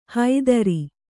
♪ haidari